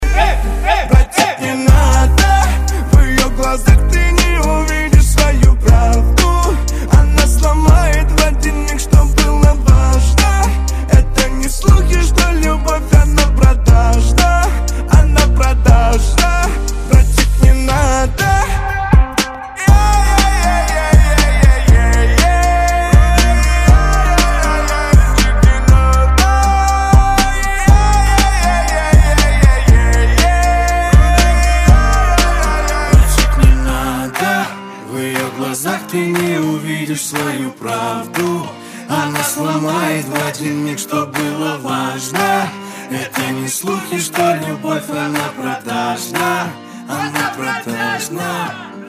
• Качество: 128, Stereo
гитара
грустные
спокойные